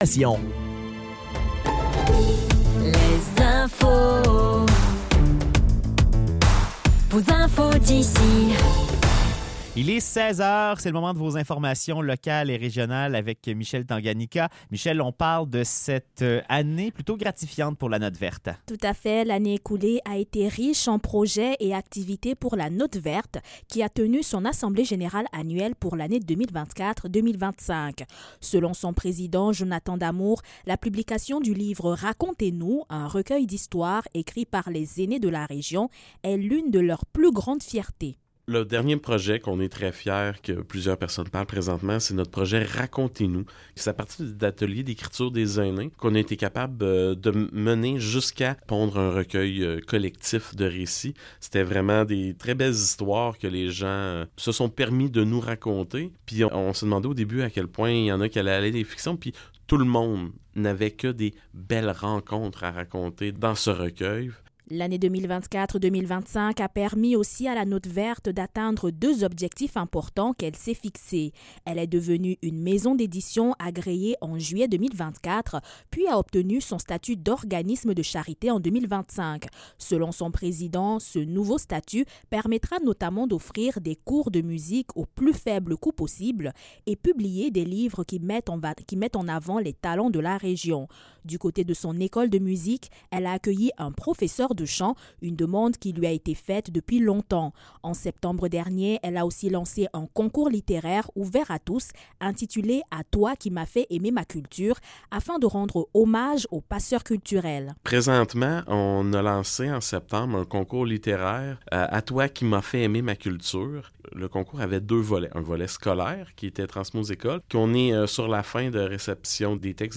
Nouvelles locales - 16 février 2026 - 16 h